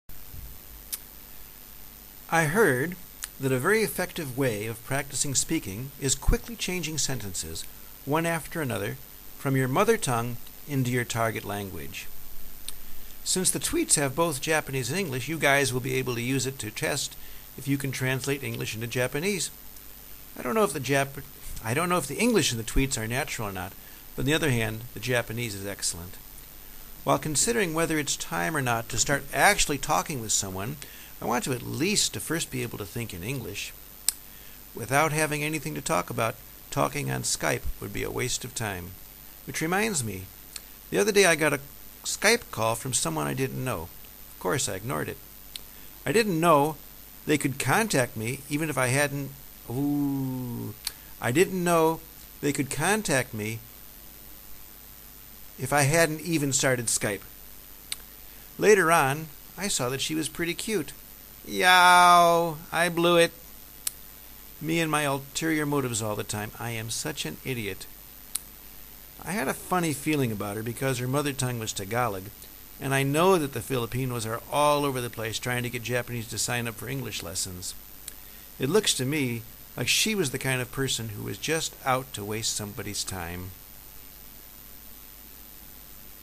I'm talking pretty fast here.